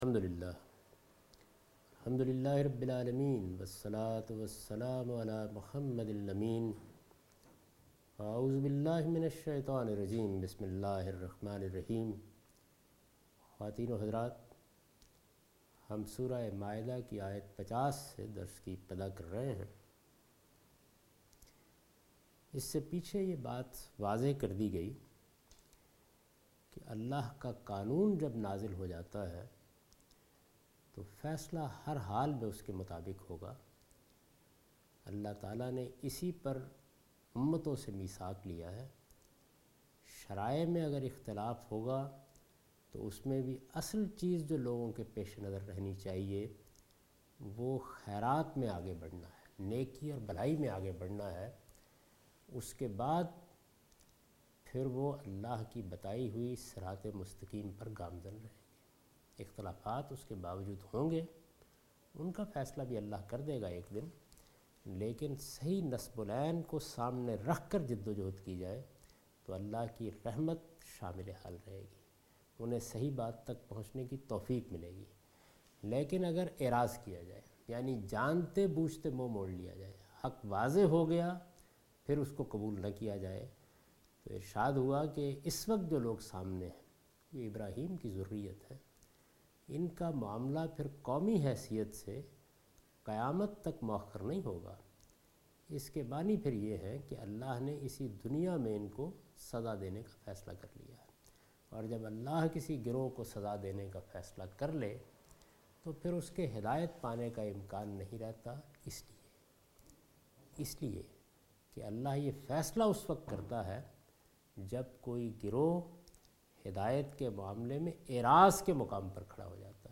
Surah Al-Maidah - A lecture of Tafseer-ul-Quran, Al-Bayan by Javed Ahmad Ghamidi. Commentary and exlanation of verse 50 to 52